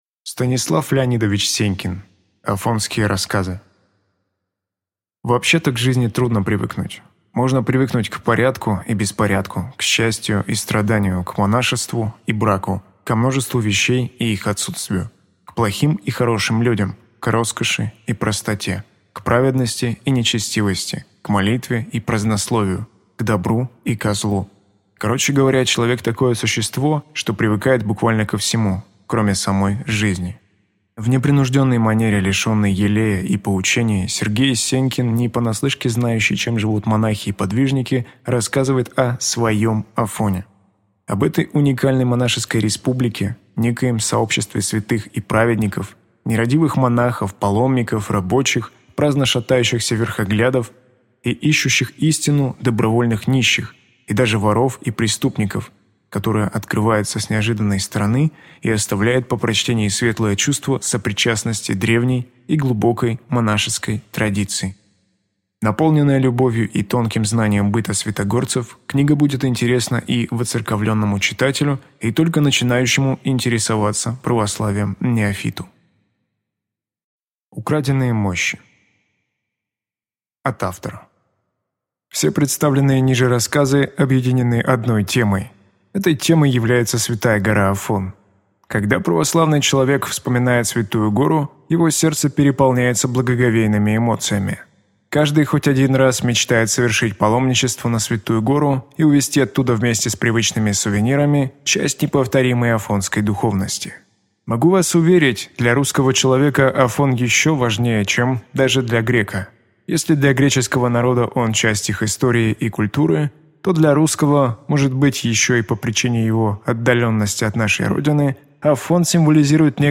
Аудиокнига Афонские рассказы | Библиотека аудиокниг